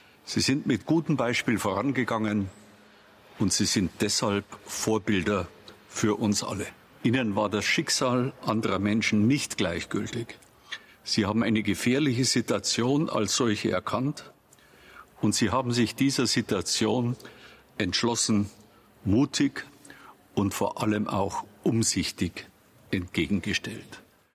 Der aktuelle Schirmherr des XY-Preises, Bundesinnenminister Horst Seehofer, hielt eine kurze Laudatio, die bereits vor einigen Tagen bei ihm in Berlin aufgezeichnet worden war.
rede-horst-seehofer-2020-cut.mp3